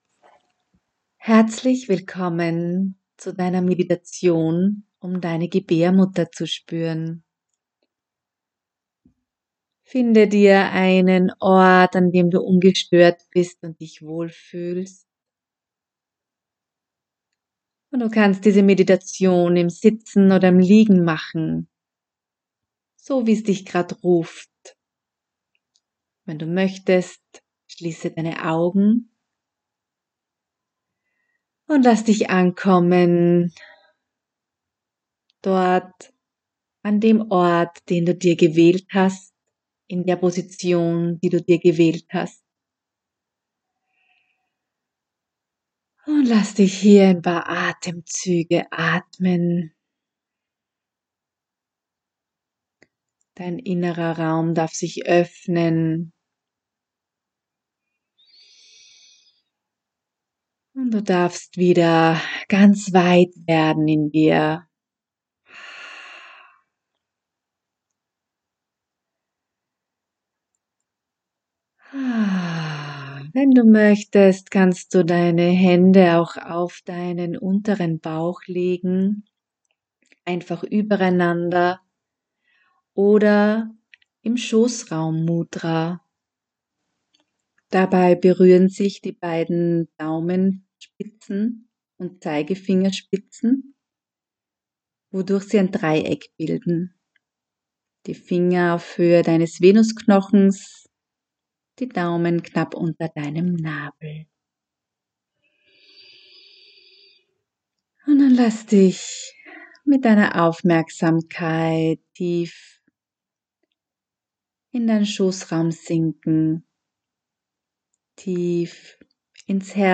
Audio-Meditationen aus dem Buch
Meditation-Die-Gebaermutter-spueren.mp3